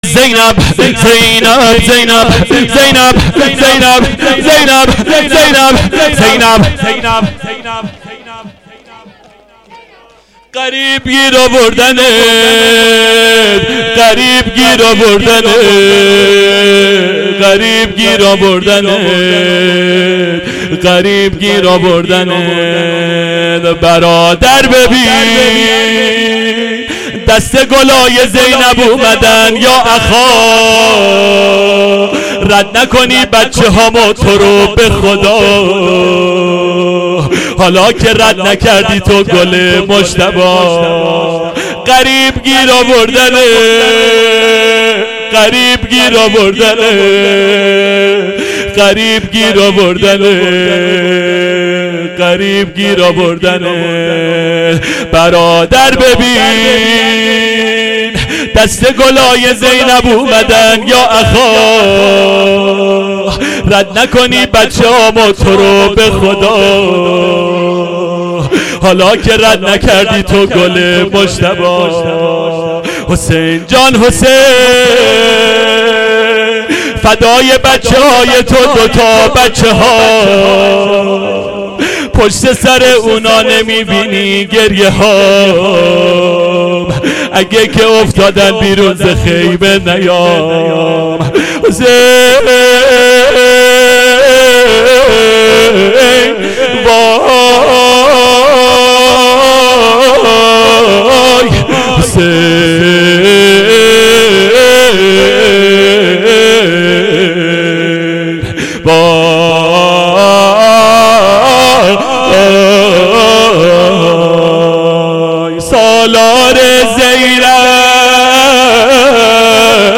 شور ایستاده شب چهارم محرم ۹۷